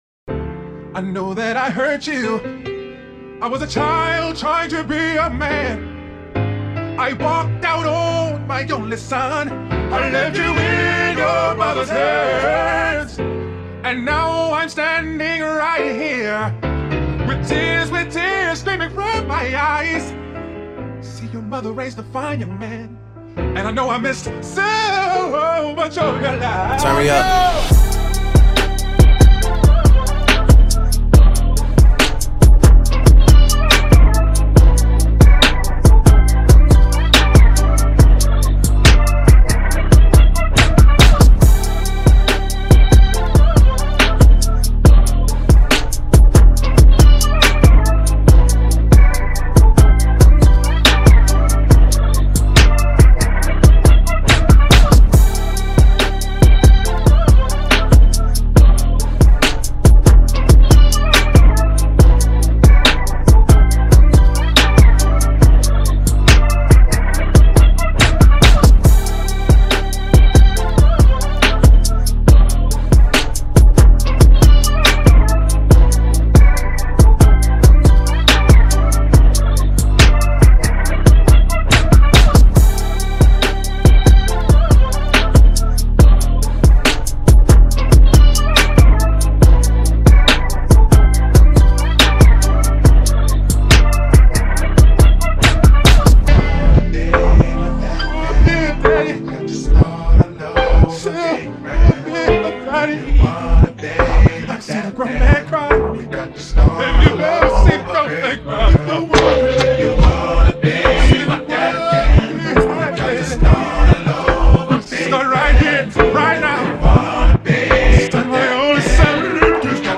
Here's the instrumental version